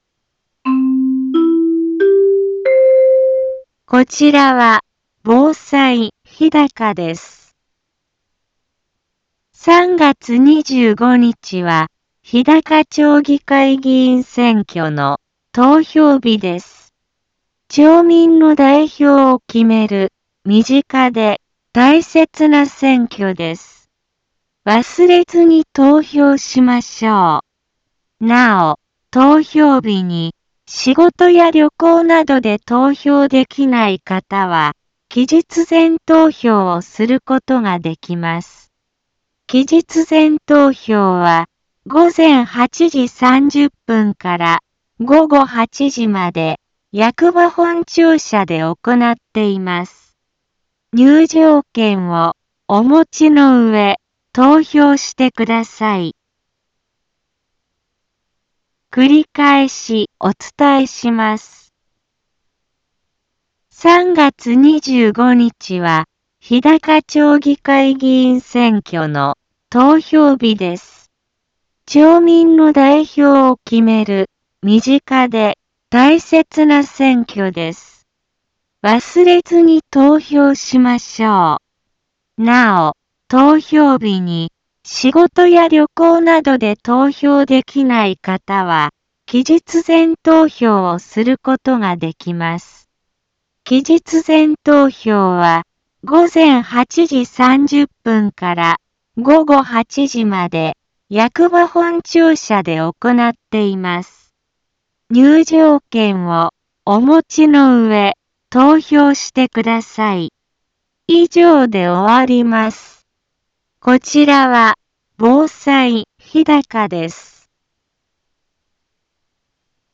Back Home 一般放送情報 音声放送 再生 一般放送情報 登録日時：2018-03-21 10:05:36 タイトル：日高町議会議員選挙のお知らせ インフォメーション：３月２５日は、日高町議会議員選挙の投票日です。